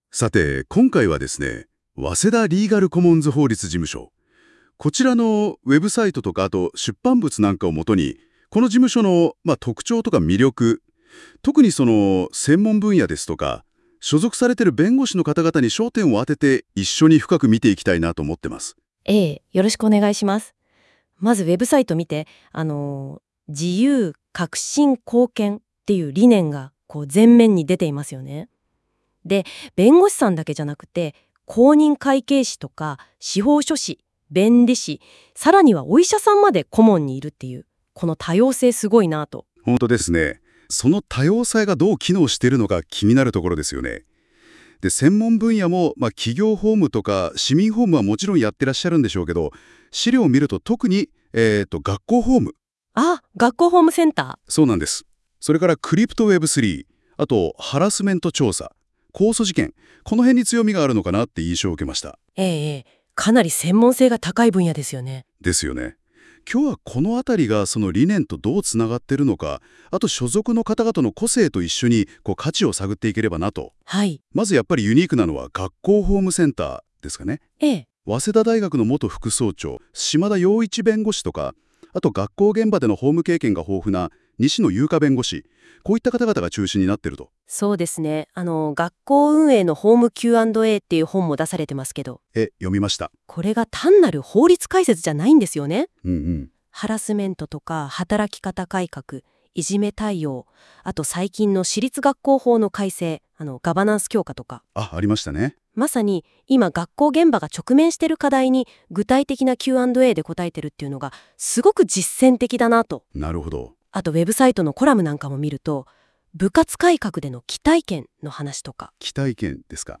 7分ほどの「ラジオ番組」です。
一部の固有名詞に誤った発音などはあるものの、取り上げられている内容には概ね間違いはありません。なによりとても流暢なやりとりで、本当にラジオパーソナリティの男女が当事務所について調べたうえで、紹介してくれている番組なのではないか、と錯覚してしまいます。
実のところこれは生成AIが作成した音声データで、当事務所のリサーチャーがNotebook LMの機能を用いて「チャチャっと」作り上げたものです。